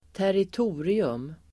Uttal: [(²)tärit'o:rium]